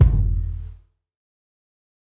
kick processed 7.wav